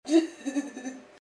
Download Funny Goofy sound effect for free.
Funny Goofy